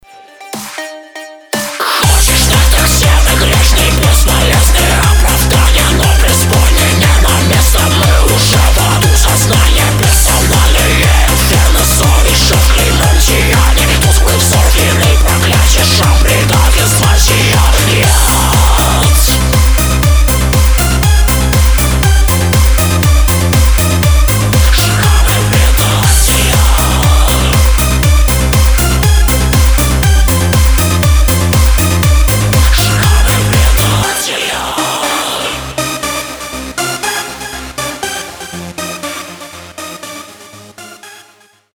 • Качество: 320, Stereo
мужской вокал
громкие
атмосферные
Electronic
пугающие
страшные
EBM
Industrial
Dark Electro
Aggrotech